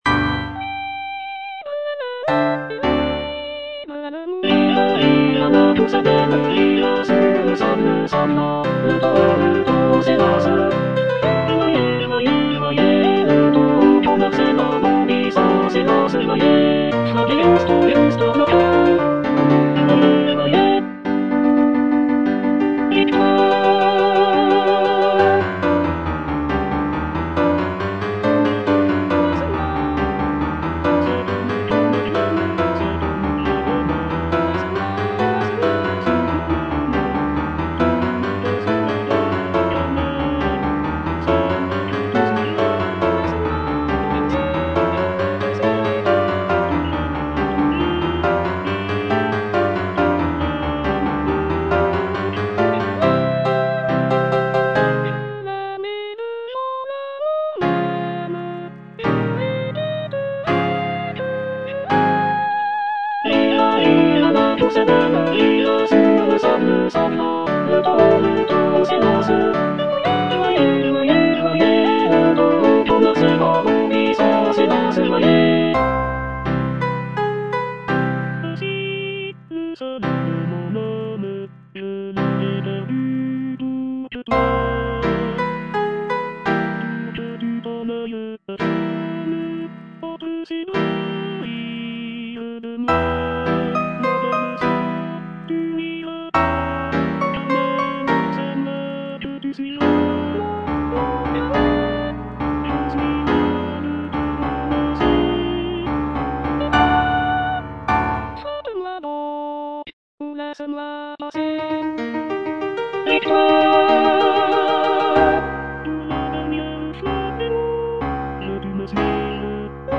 G. BIZET - CHOIRS FROM "CARMEN" Final chorus (All voices) Ads stop: